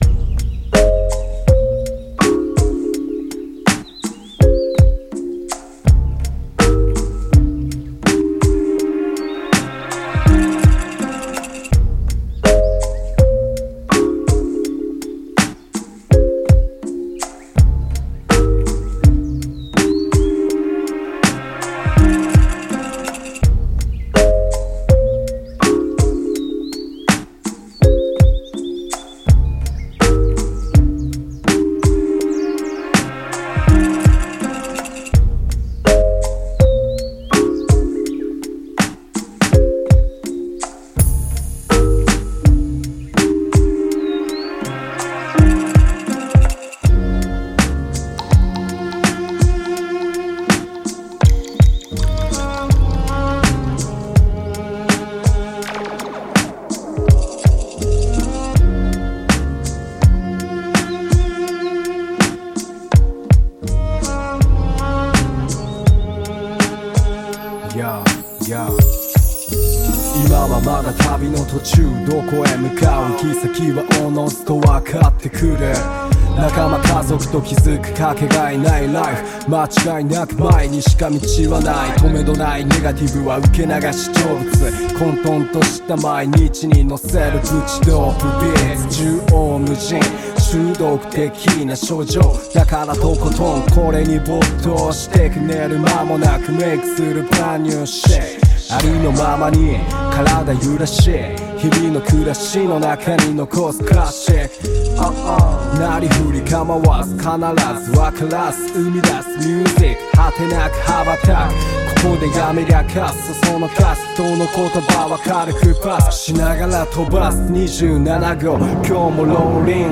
poet spoken words and reggae gospel artist.